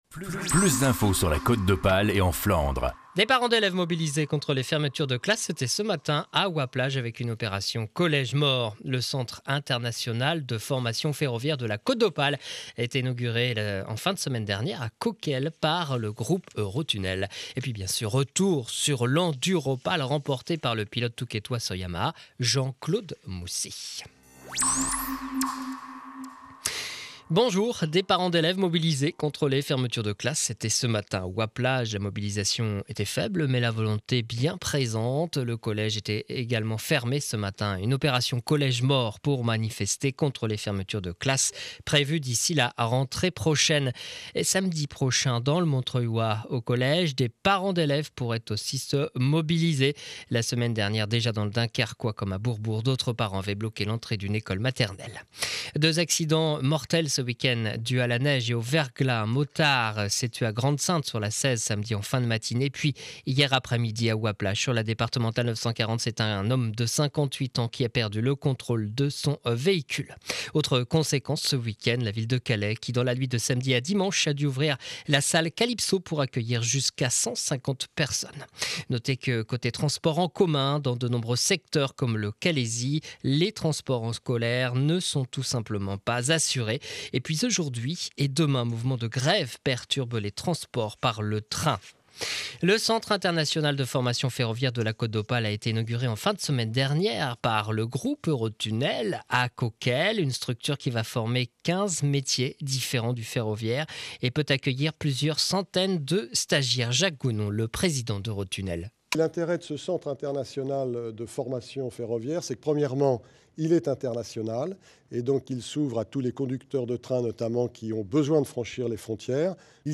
Edition de 12h journal de Calais